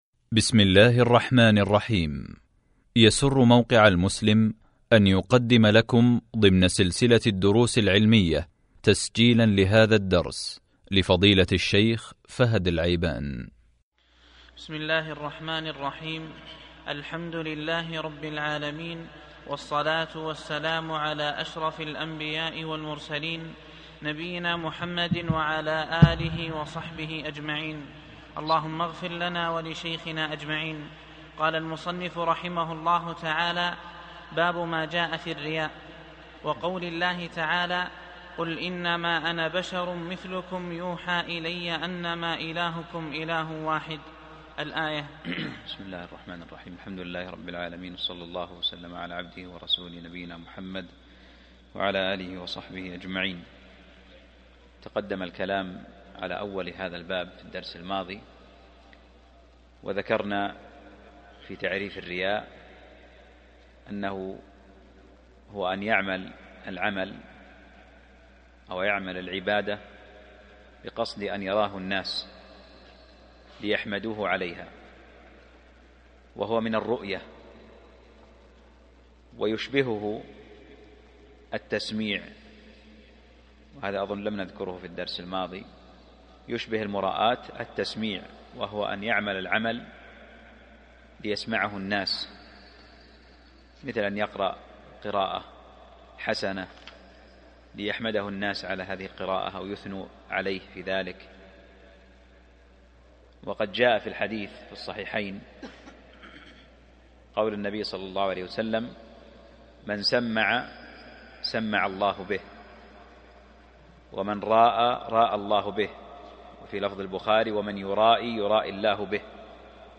الدرس (36) من شرح كتاب التوحيد | موقع المسلم